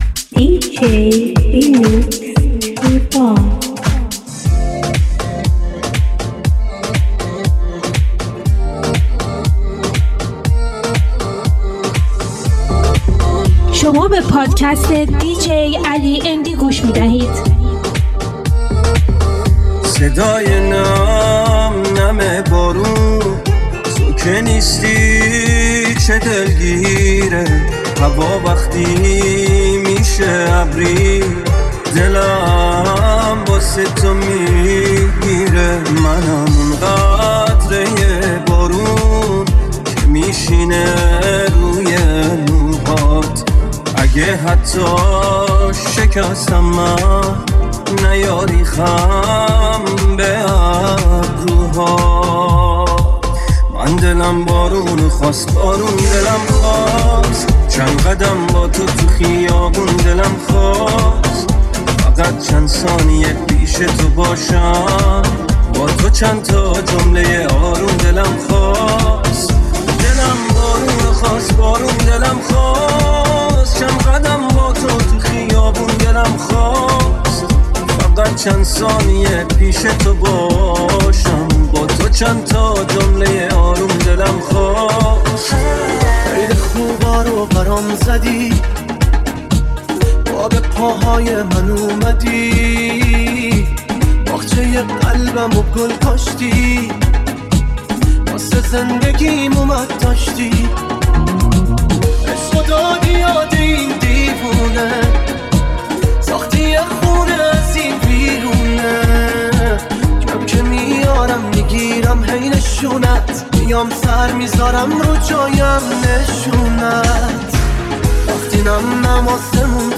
ریمیکس طولانی